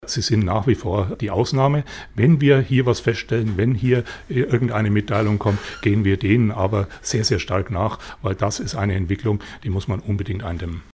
Die Verkehrsbilanz des Polizeipräsidiums Unterfranken für das vergangene Jahr ist durchaus positiv gewesen – es hat aber vermehrt Raserunfälle gegeben, sagt Präsident Gerhart Kallert: